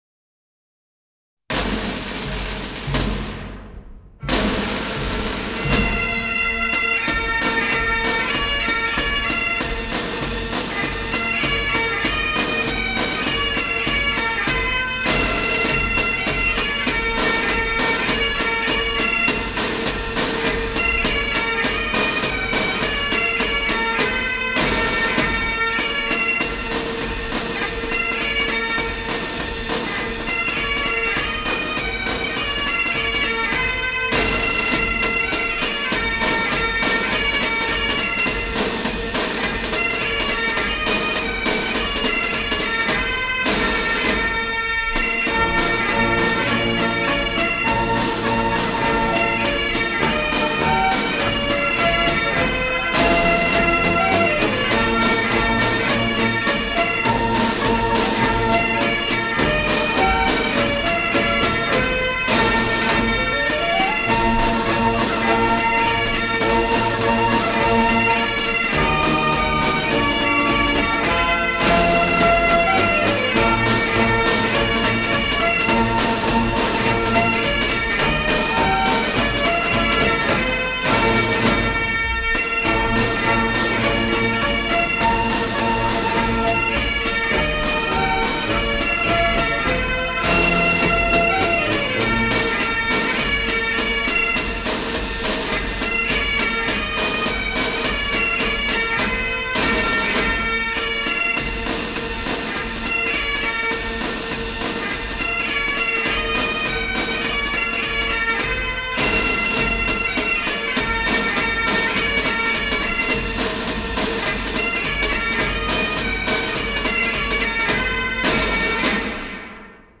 Sounds of the bagpipe download and listen online
• Category: Bagpipes
• Quality: High